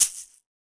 Shaken Shaker 03.wav